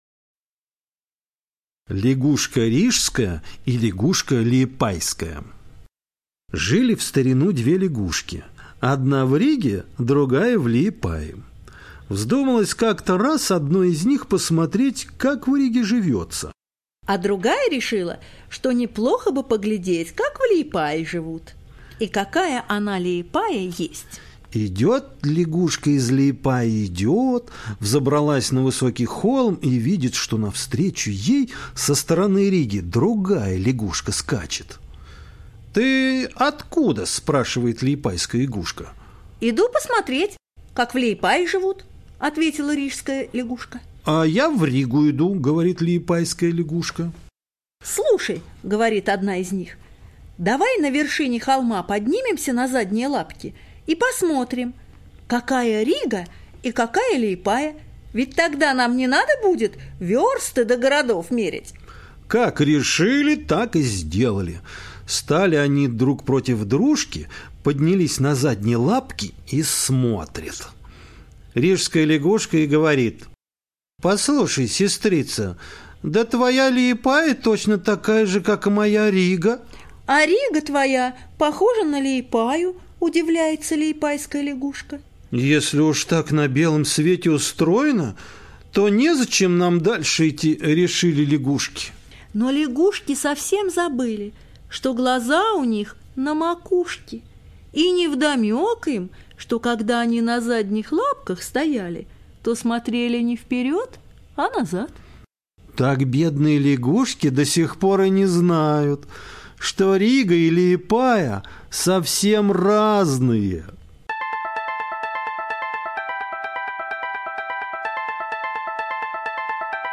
Лягушка рижская и лягушка лиепайская - латышская аудиосказка - слушать онлайн